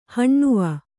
♪ haṇṇuva